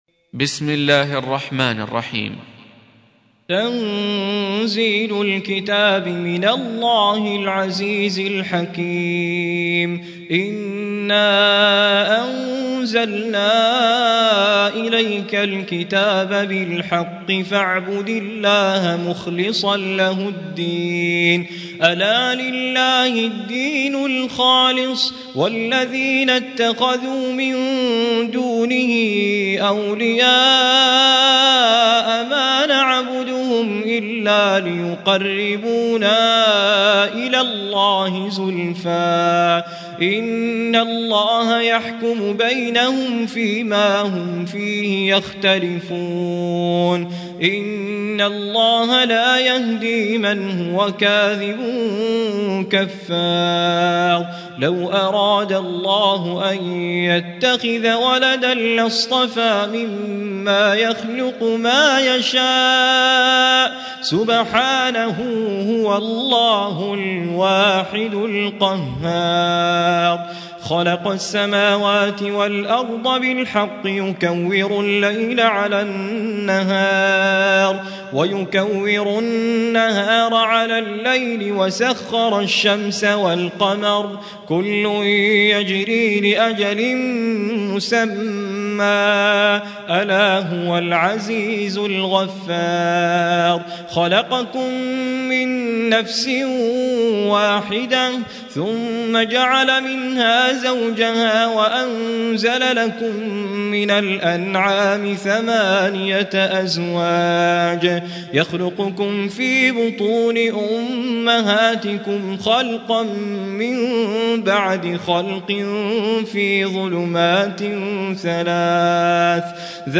Surah Repeating تكرار السورة Download Surah حمّل السورة Reciting Murattalah Audio for 39. Surah Az-Zumar سورة الزمر N.B *Surah Includes Al-Basmalah Reciters Sequents تتابع التلاوات Reciters Repeats تكرار التلاوات